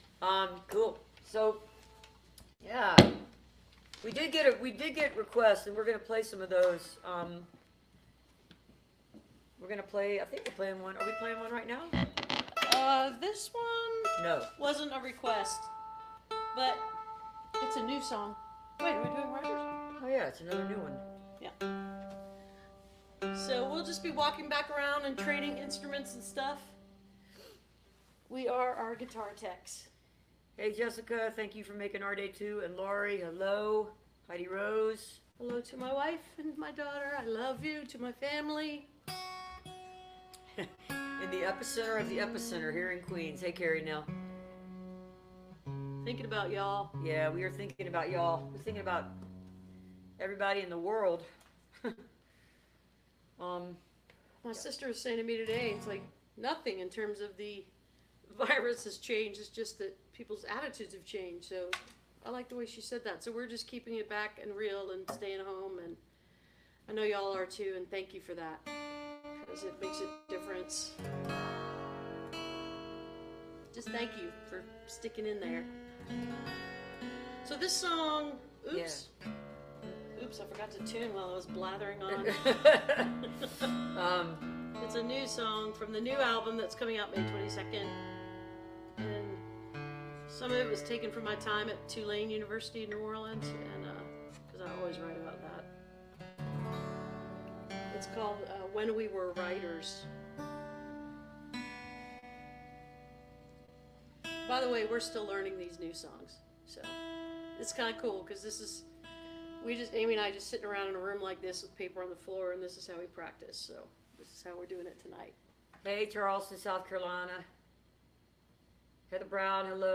lifeblood: bootlegs: 2020-05-07: song request livestream - facebook/instagram/youtube
(captured from the facebook live video stream)
05. talking with the crowd (2:44)